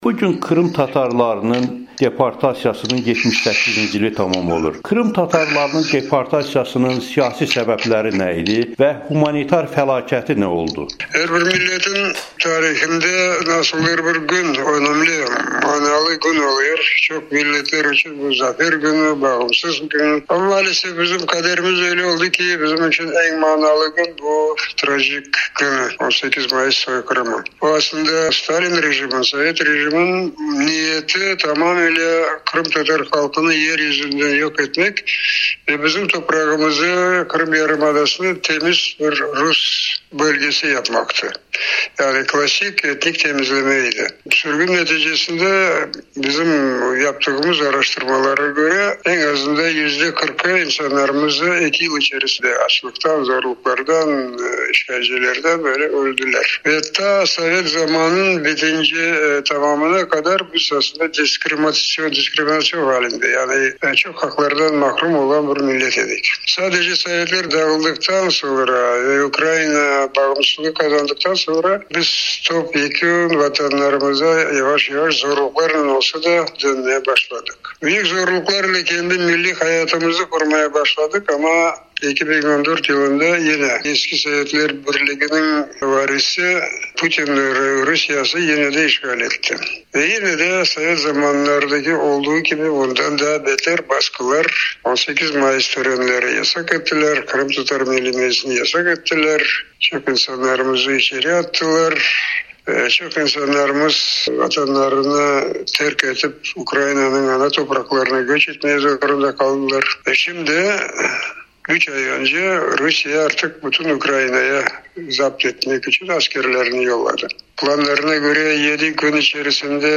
Krım tatarlarının lideri, Ukrayna Ali Radasının deputatı Mustafa Cəmil Krımoğlu Amerikanın Səsinə müsahibədə bildirib ki, 1944-cü ilin 18 may soyqırımı Krım tatar xaqlının faciə günüdür.